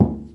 冲击、撞击、摩擦 工具 " 塑料桶咚咚2
Tag: 工具 工具 崩溃 砰的一声 塑料 摩擦 金属 冲击